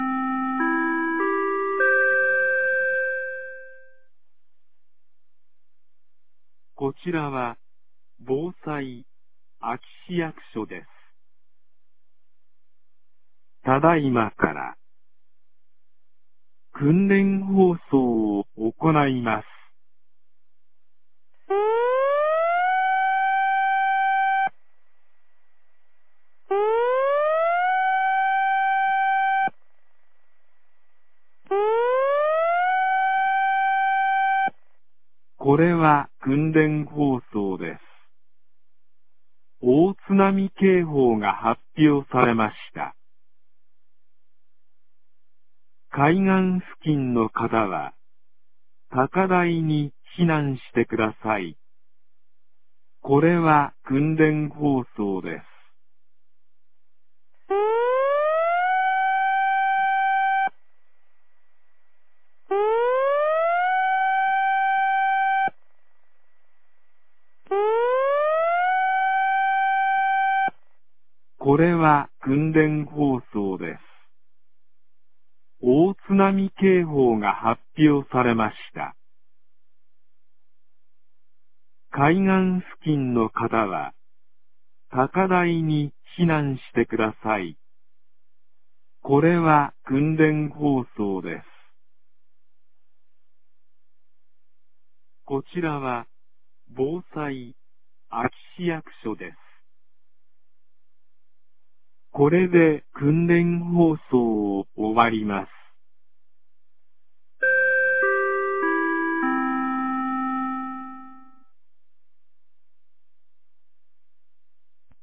2025年09月07日 09時10分に、安芸市より全地区へ放送がありました。